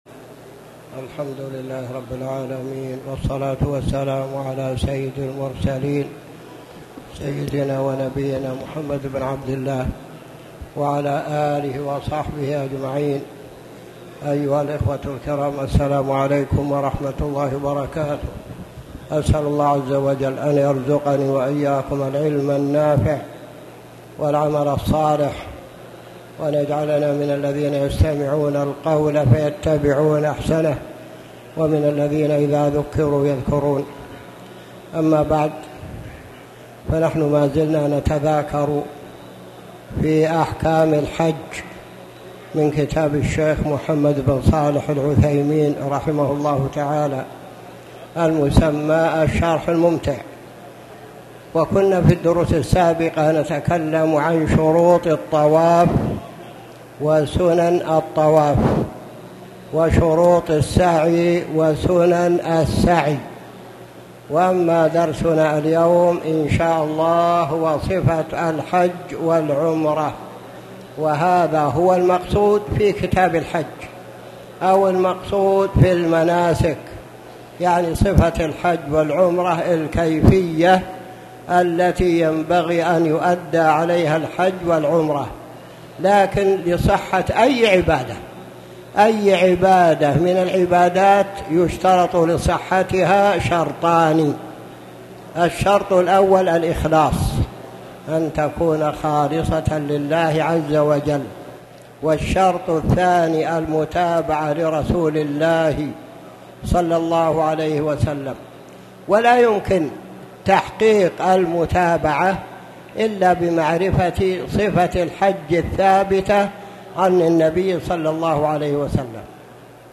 تاريخ النشر ٢٨ ذو الحجة ١٤٣٨ هـ المكان: المسجد الحرام الشيخ